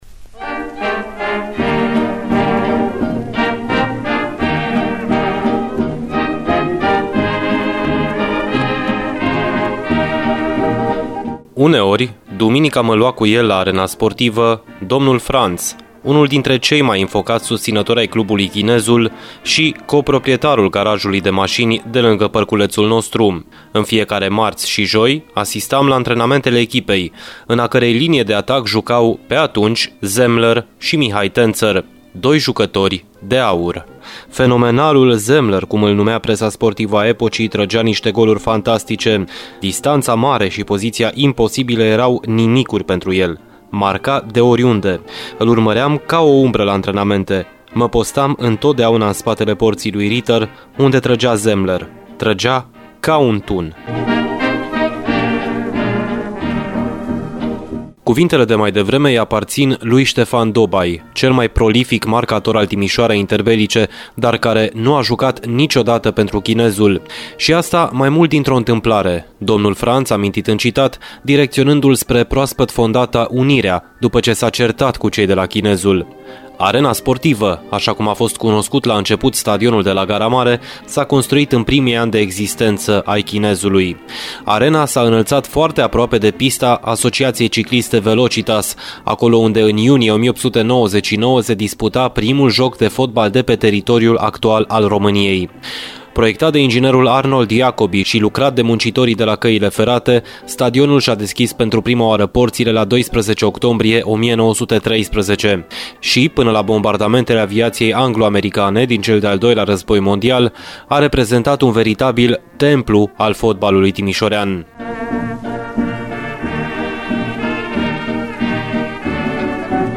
a fost difuzat astăzi, la „Arena Radio”.